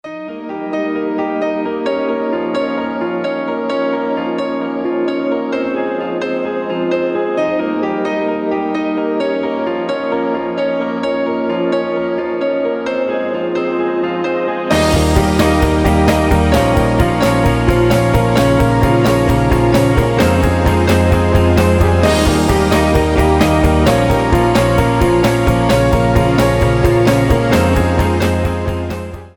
Красивое пианино